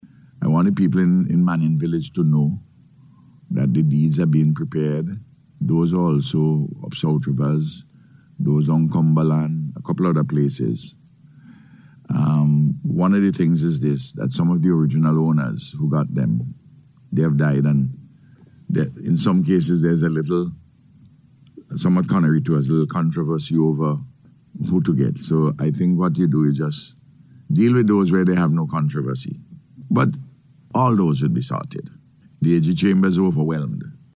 Prime Minister Dr. Ralph Gonsalves made the announcement on NBC’s Face to Face Programme this morning.